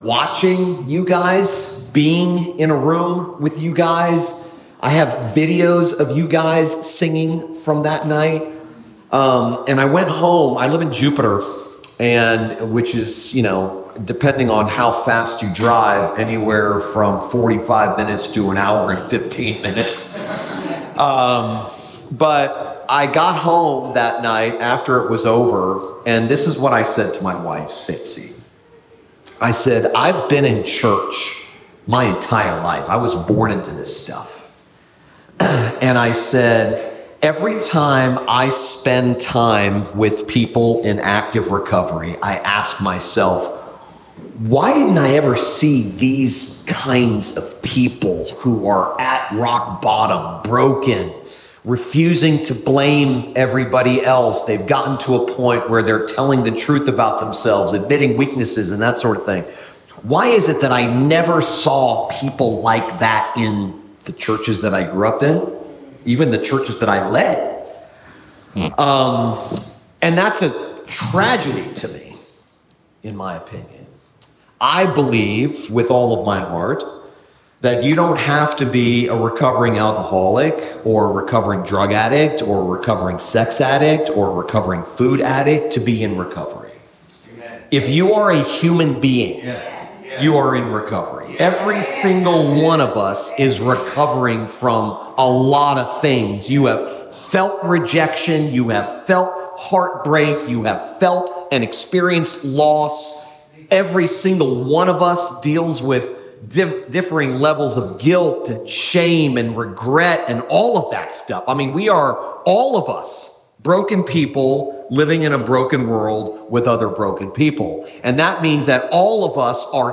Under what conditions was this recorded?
at the Recovery Bible Study, Fort Lauderdale FL